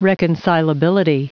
Prononciation du mot reconcilability en anglais (fichier audio)
Prononciation du mot : reconcilability